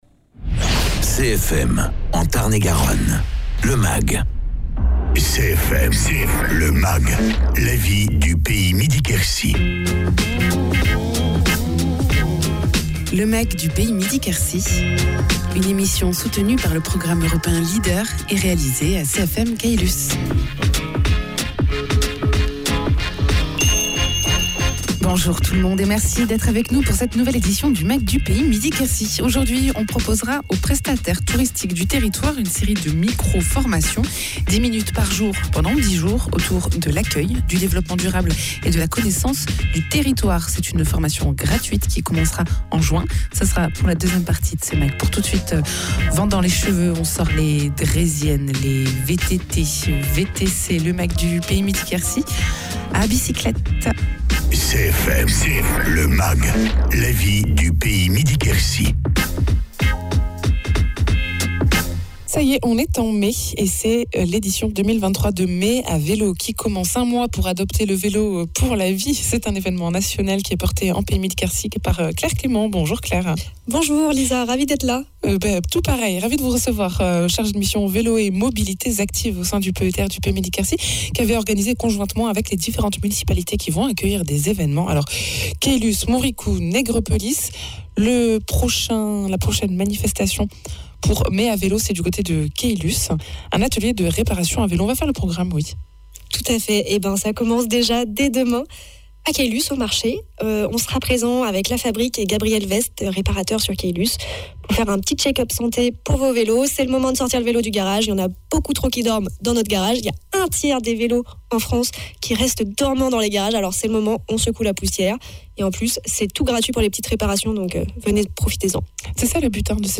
Le programme des animations organisées par le Pays Midi Quercy dans le cadre de l’opération "Mai à vélo" : challenge, ateliers, bourses au vélo, inauguration de boucles VTT... Et également dans ce mag, des micro-formations gratuites destinées aux professionnels du tourisme autour de l’accueil, du développement durable et de la connaissance du territoire.